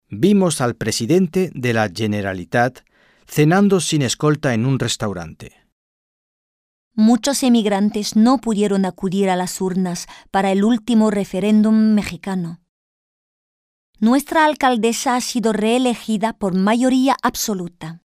Un peu de conversation - Les institutions